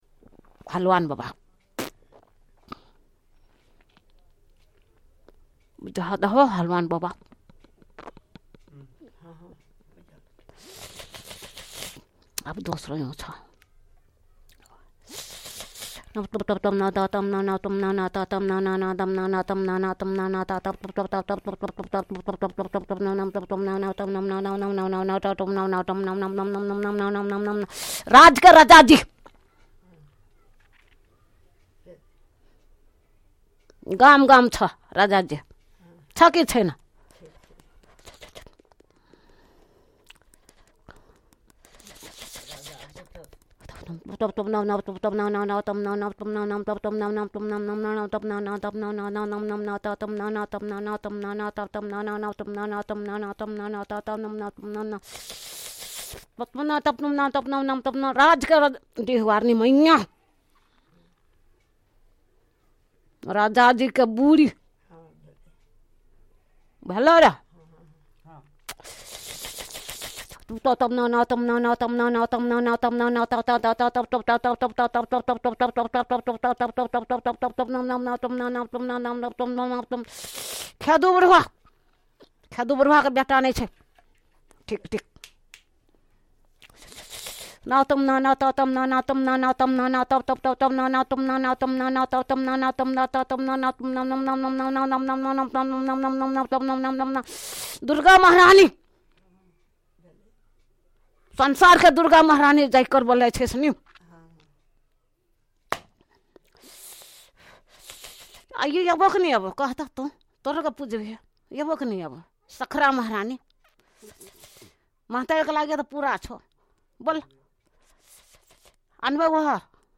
अस्‌पस्‌ट भासा | मध्य-पूर्वीया थारू
यी पेजमे साधारन लोकसबके नई बुझईबला खालके आवाजसब समाबेस कईरके राखने छैइ ।